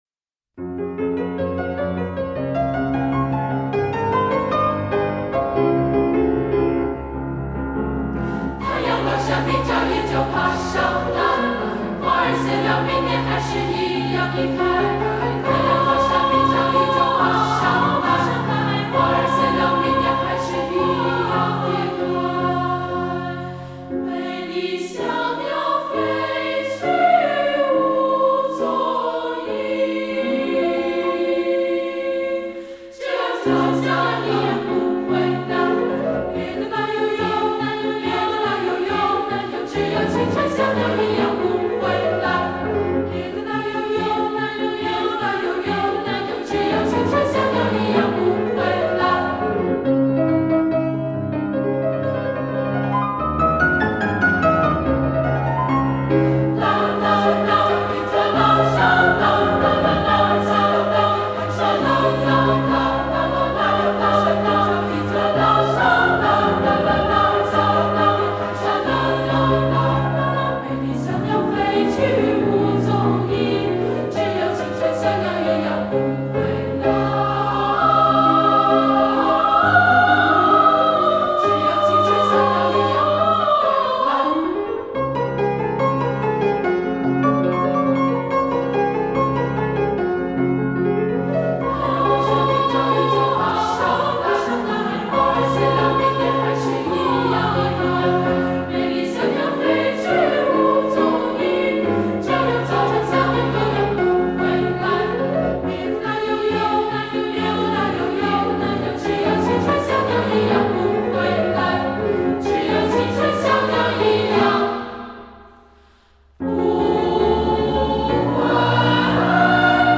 音乐类型：流行音乐
天使般纯净天真 山风流水般流畅生动
合唱团的音色层次清晰，演唱风格童趣盎然。